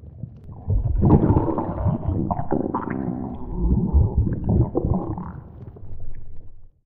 Compresses and normalizes vore sounds 2021-07-18 06:21:01 +00:00 58 KiB Raw History
death8.ogg